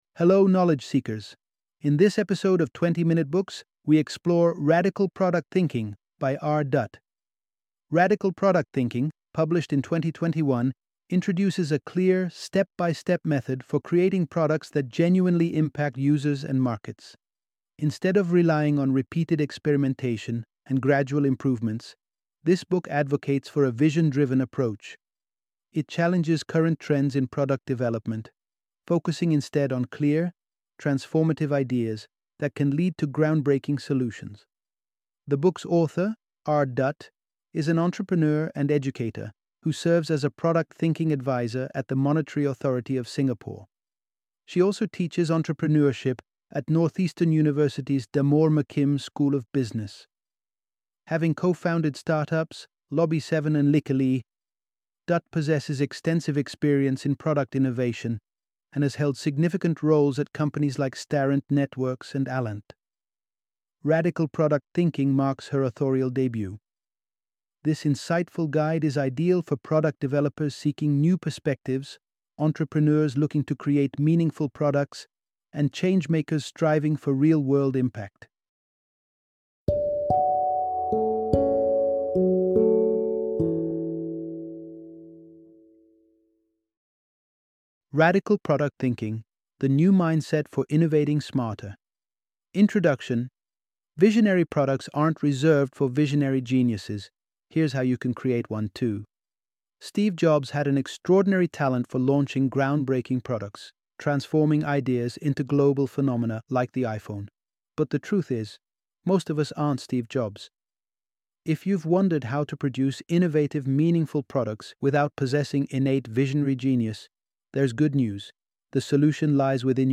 Radical Product Thinking - Audiobook Summary